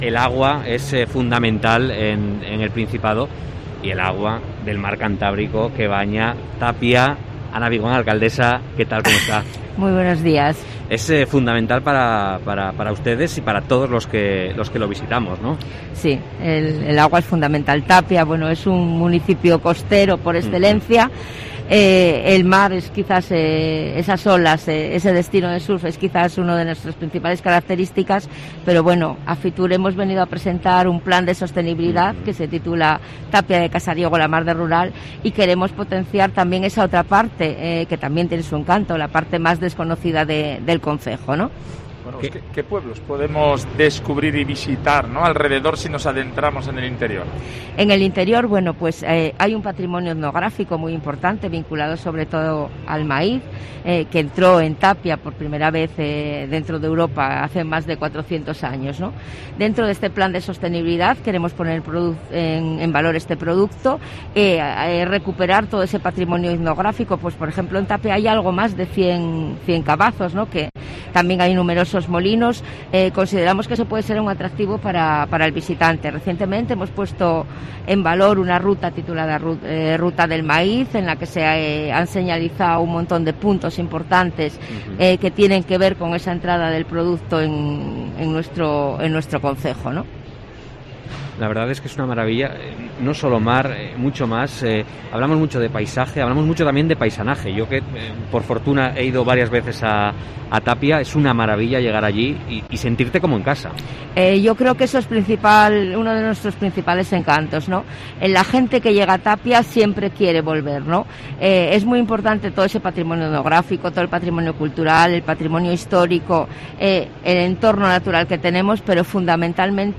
Fitur 2022: Entrevista a Ana María Vigón, alcaldesa de Tapia de Casariego